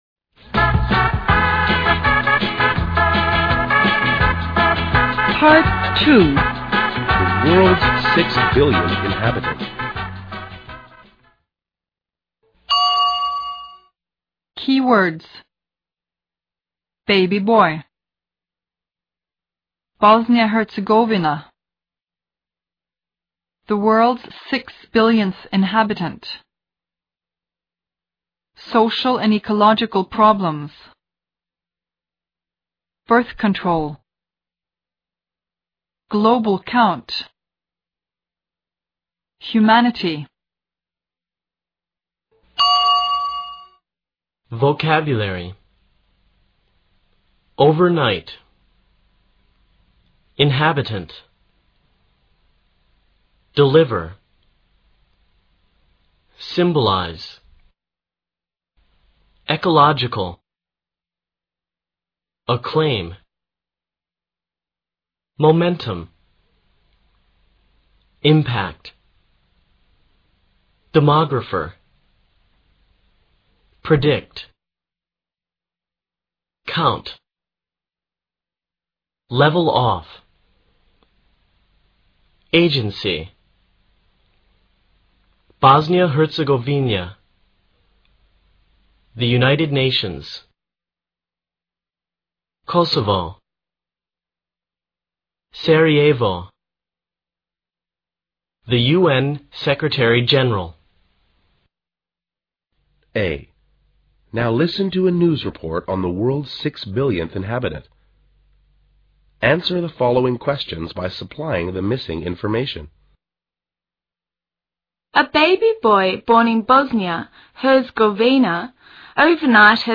C. Now listen to the a faster presentation of the material.